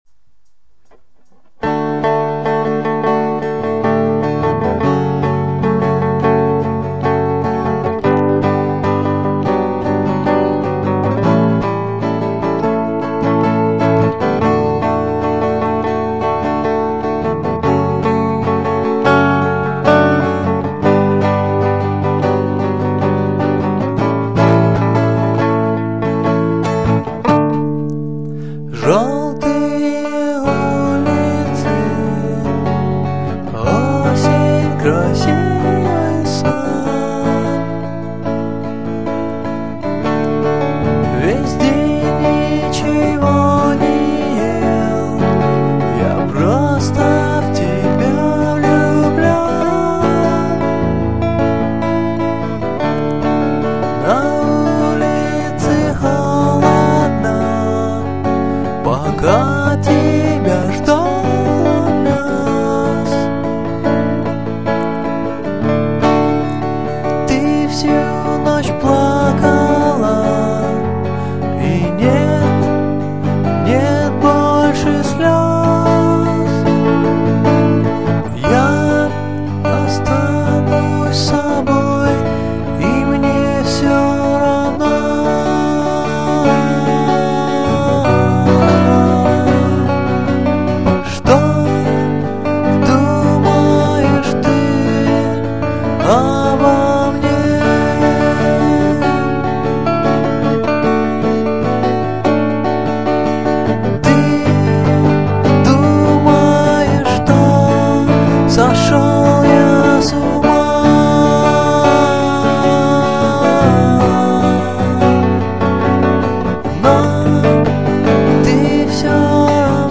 панк-рок группы